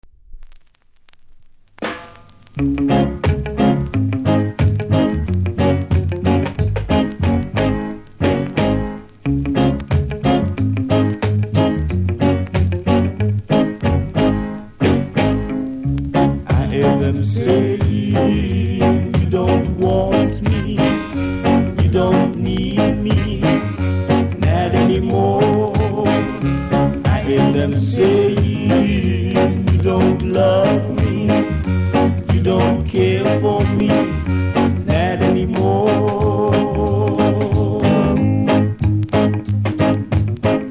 両面キズはそこそこありますがノイズはそれほどでも無いので試聴で確認下さい。